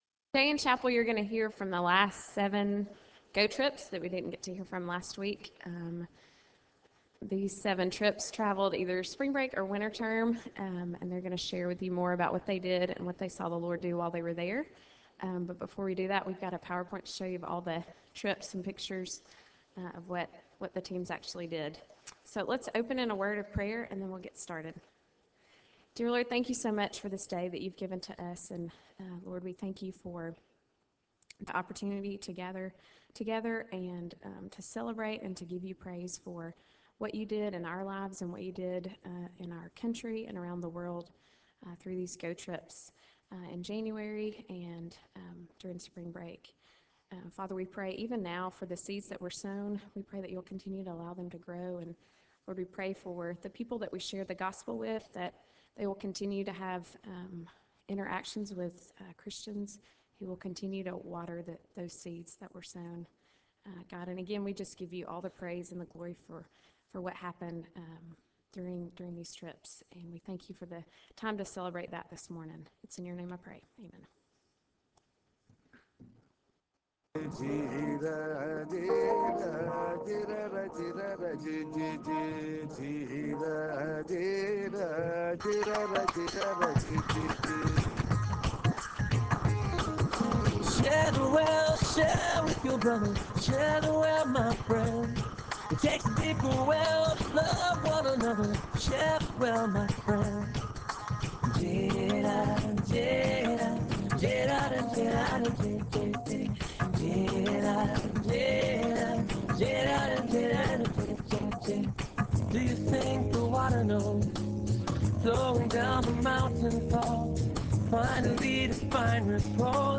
Chapel Service: GO Trip Reports
Students who participated in Global Outreach "GO" Trips during Spring Break and Winter Term will be sharing about their experiences.